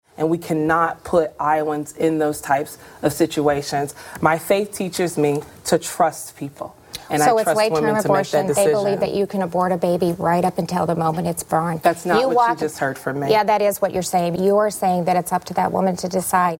abortion and carbon pipelines during their debate last night on Iowa PBS.